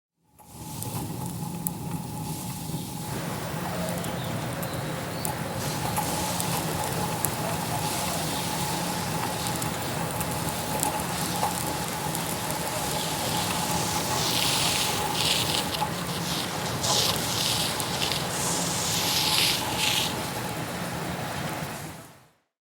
Steamworks Ambiance
Steamworks Ambiance is a free ambient sound effect available for download in MP3 format.
Steamworks Ambiance.mp3